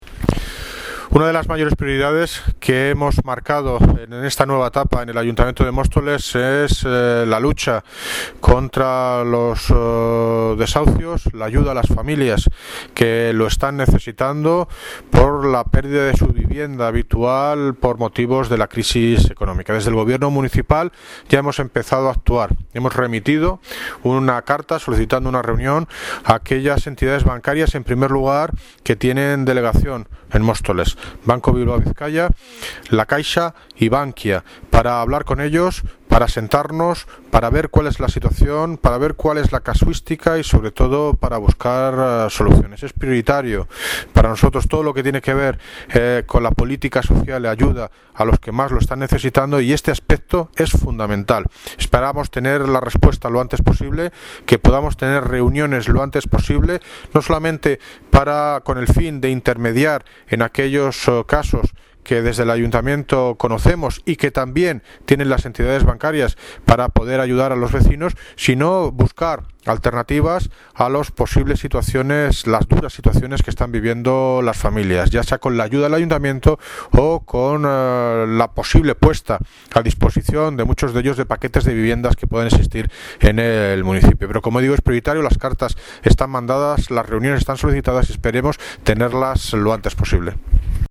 Audio - David Lucas (Alcalde de Móstoles) Sobre la creación de la oficina antideshaucio
Audio - David Lucas (Alcalde de Móstoles) Sobre la creación de la oficina antideshaucio.mp3